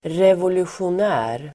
Ladda ner uttalet
revolutionär adjektiv, revolutionary Uttal: [revolusjon'ä:r] Böjningar: revolutionärt, revolutionära Synonymer: upprorisk Definition: som strävar efter revolution Exempel: revolutionära tankar (revolutionary ideas)